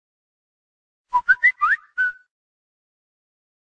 Sound Effects
Whatsapp Oficial Alert